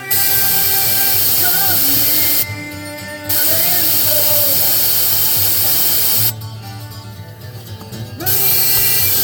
My new Samsung Galaxy S7 has a severe issue with audio out via the headphone jack.
The phone is hooked to my vehicle’s radio via the headphone jack.
Please pardon the low volume. I just used my old Droid Razr Maxx HD to record the audio.
Here is where the noise/hiss comes and goes:
samsung_s7_audionoise_variable.mp3